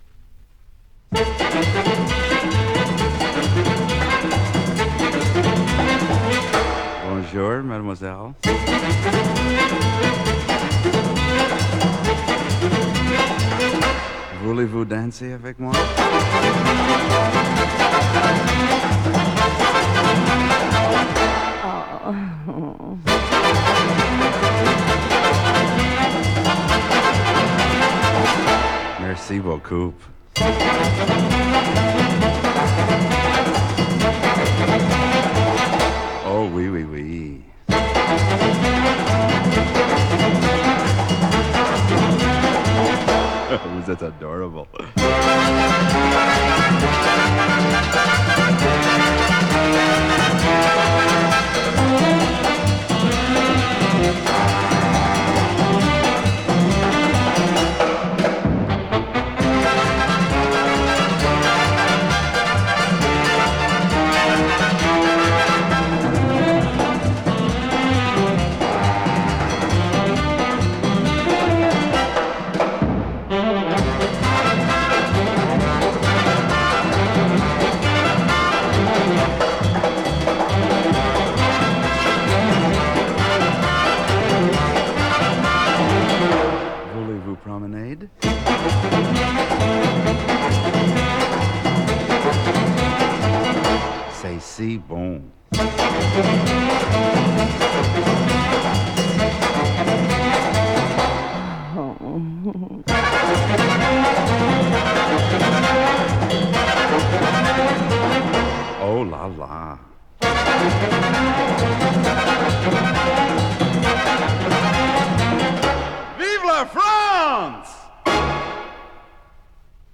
Instrumental music
Жанр: Pop, Jazz, Easy Listening